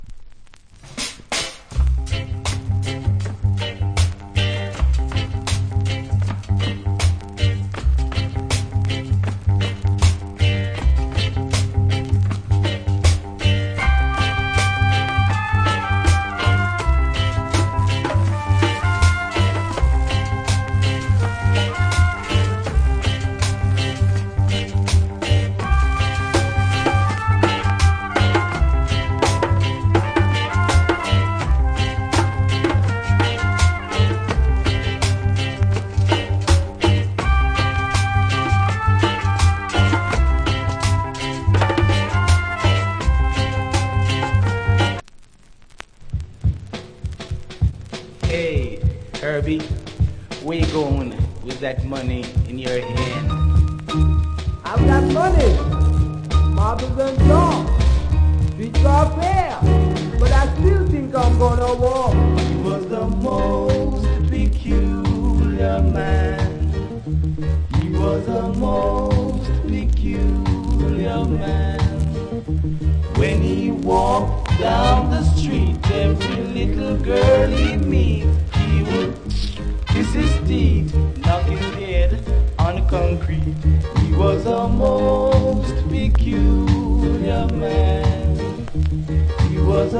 Hard To Find Clear Sounds.